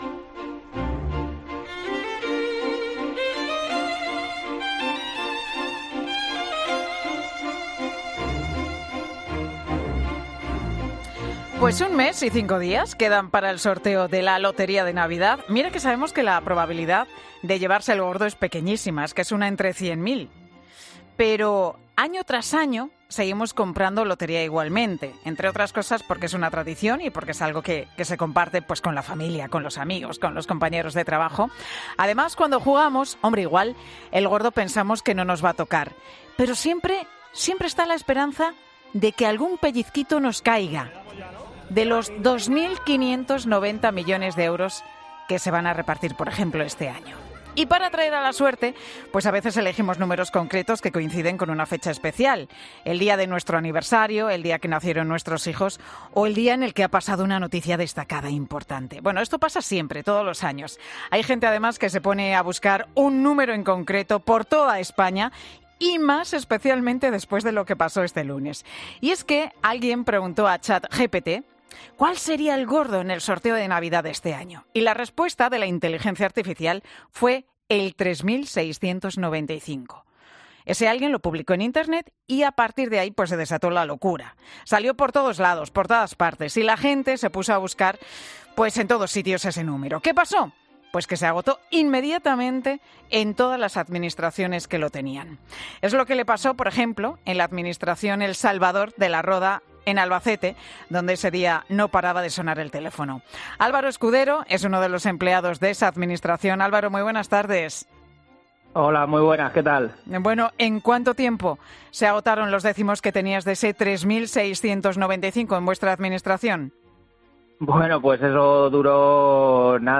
"El teléfono no paraba de sonar y pensábamos, '¿qué ocurre con este número?' Ya nos lo dijo algún cliente, era una locura" contaba entre risas.